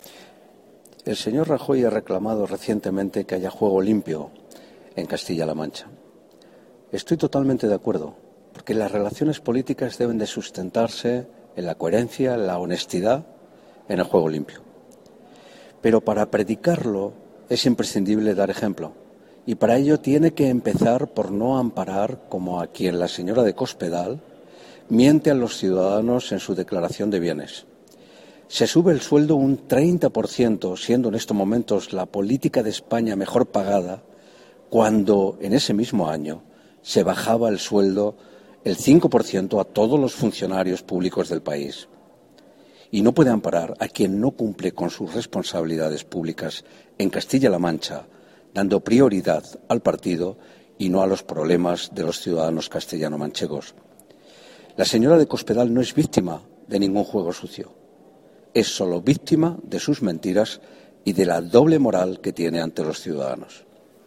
Cortes de audio de la rueda de prensa
audio_fernando_moraleda.mp3